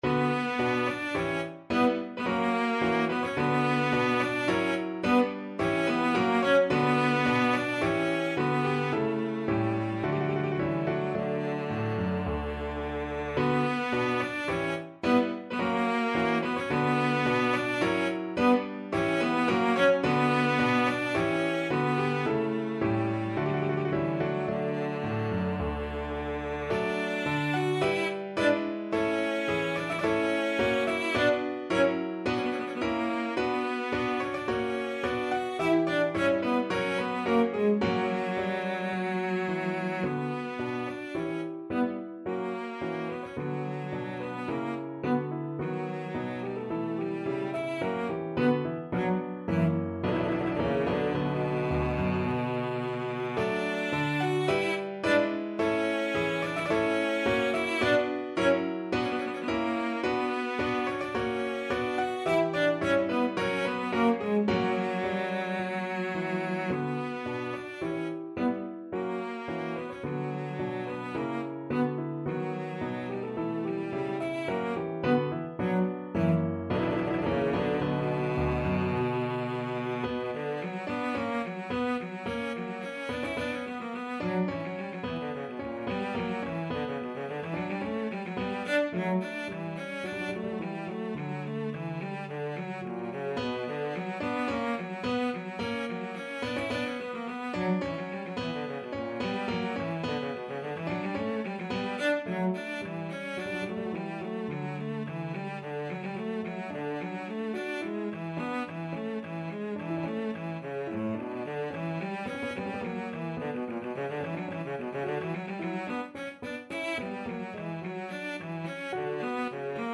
Cello
3/4 (View more 3/4 Music)
~ = 54 Moderato
B minor (Sounding Pitch) (View more B minor Music for Cello )
Classical (View more Classical Cello Music)
bach_polonaise_double_bwv_1067_VLC.mp3